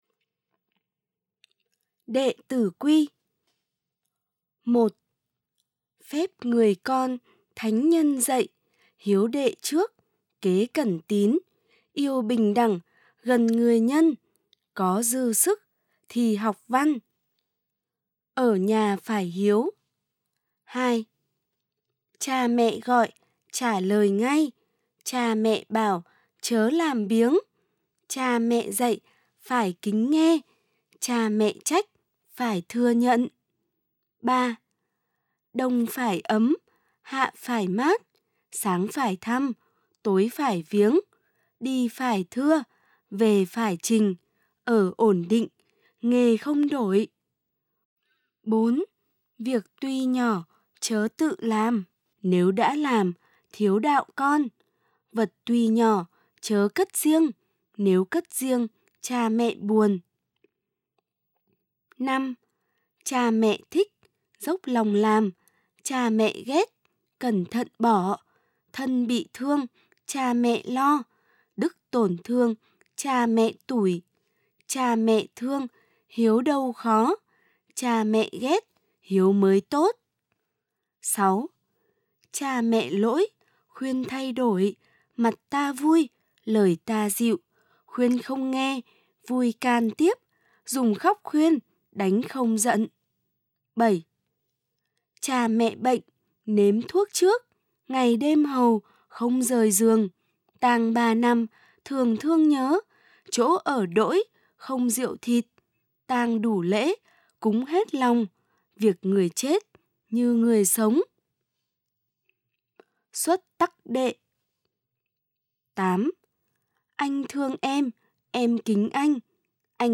Nghe đọc bản nguyên chất